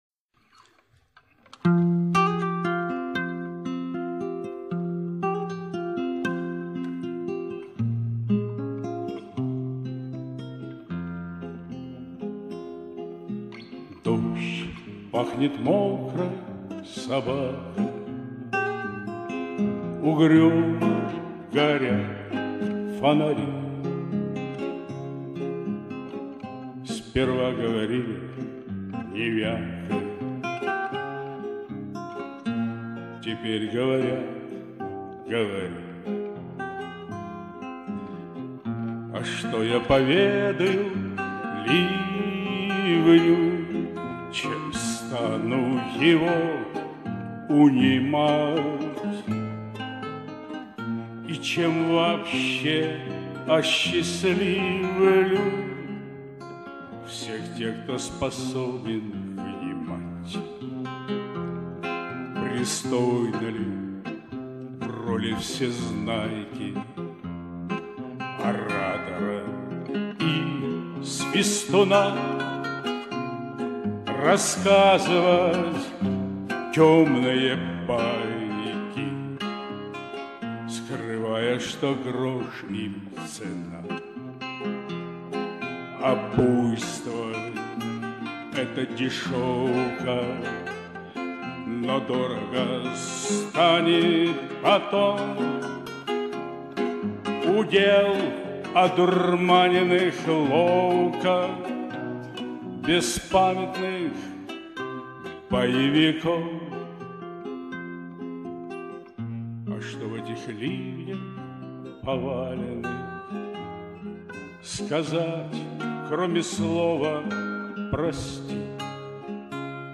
Исполнение из видеоролика.